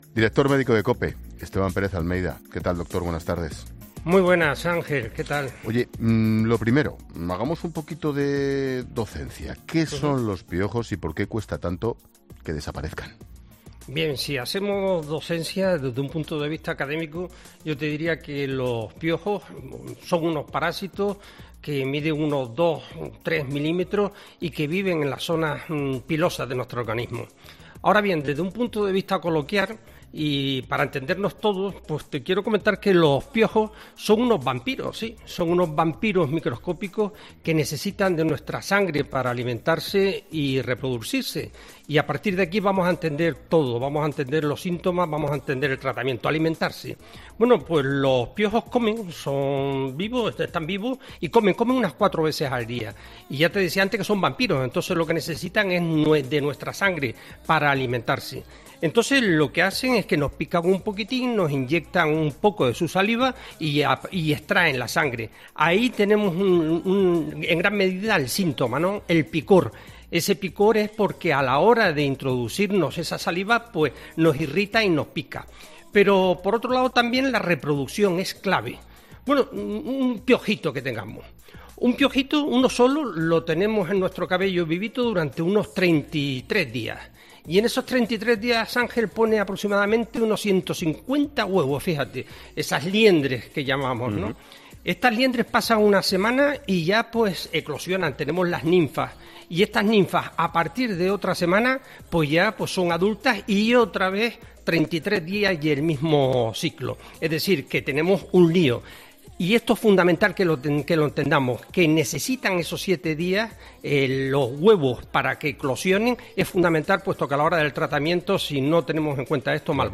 La Linterna_Angel Exposito_Imagen principal